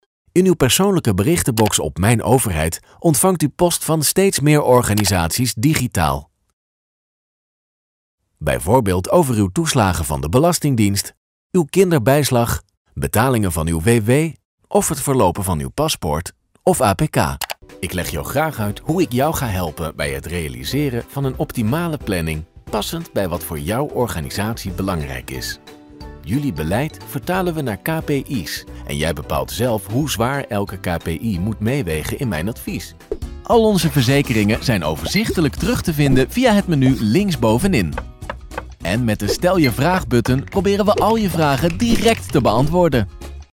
Comercial, Profundo, Amable, Cálida, Empresarial
Corporativo